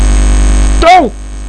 doh!.wav